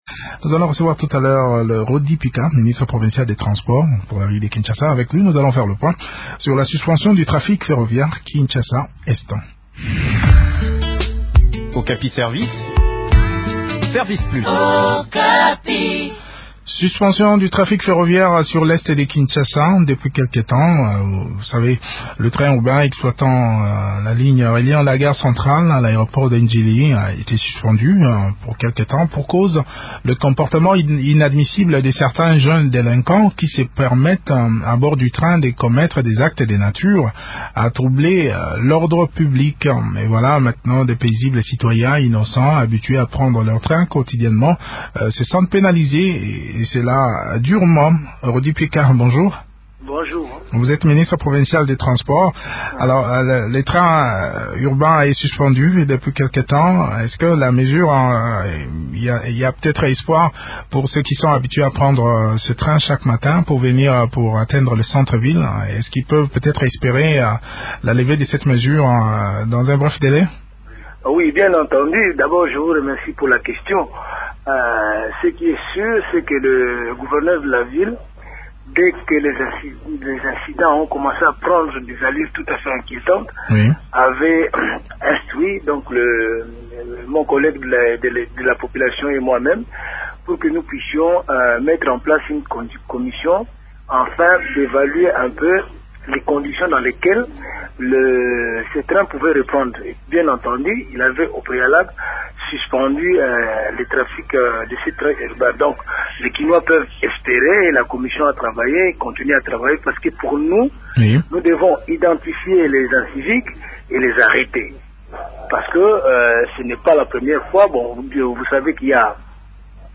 A quand la reprise du trafic ? Rody Mpika, ministre provincial de transport répond à Okapi Service.